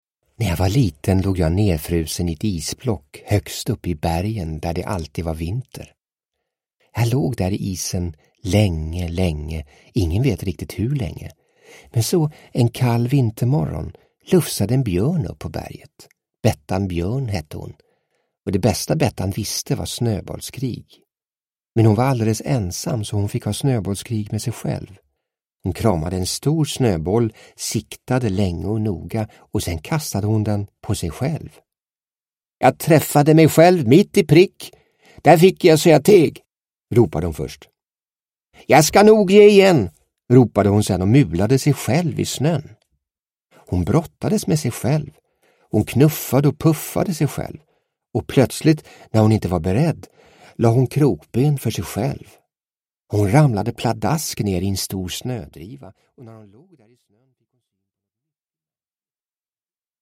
Doris – Ljudbok – Laddas ner